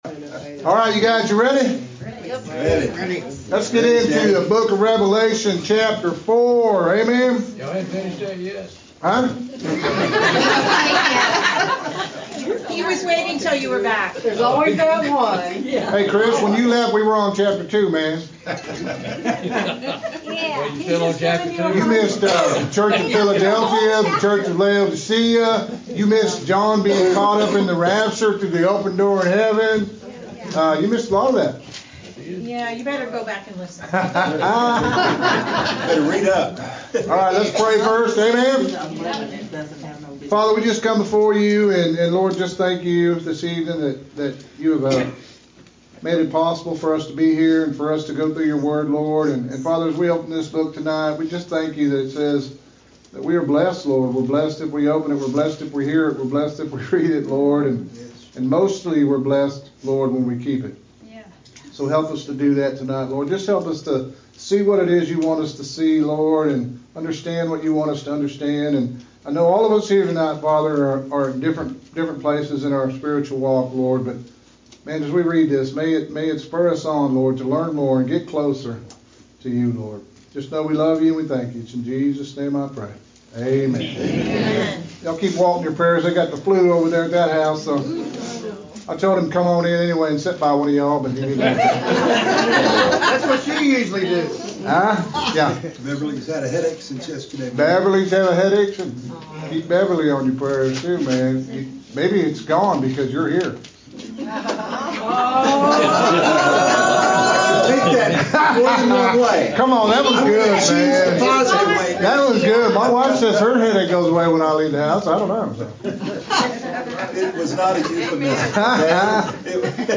Interactive Bible Study